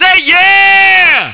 yeah.wav